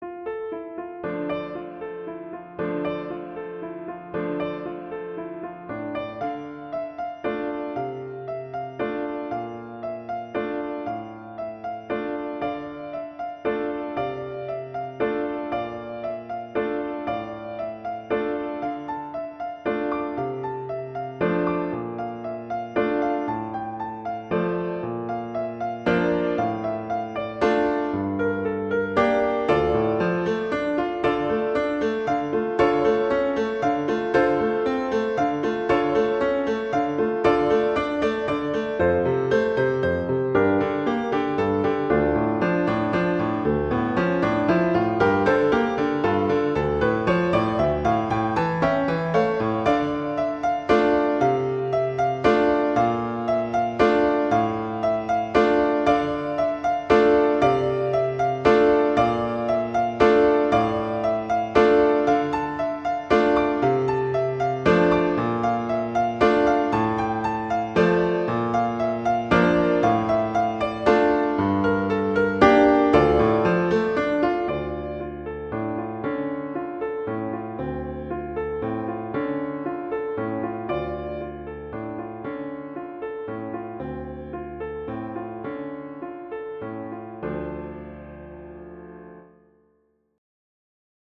arrangement for piano solo
F major
♩=116 BPM
D2-D6